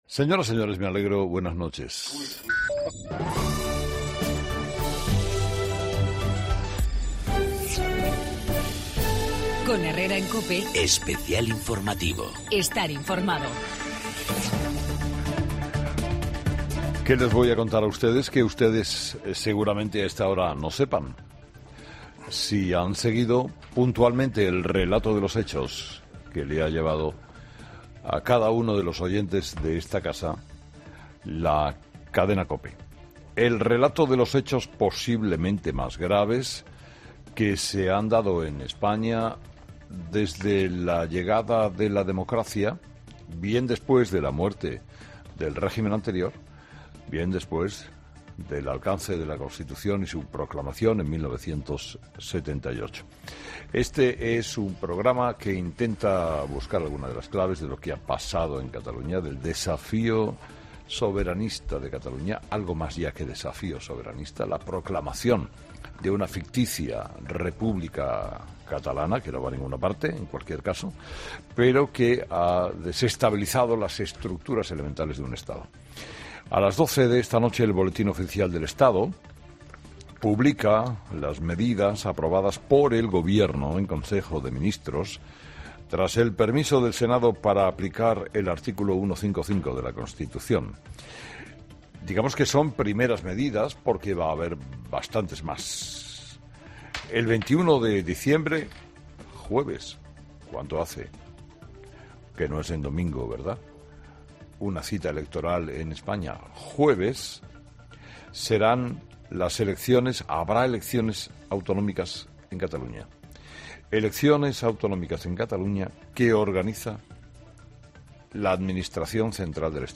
COPE realizó una programación especial a la que se unieron todos los comunicadores y un gran número de analistas políticos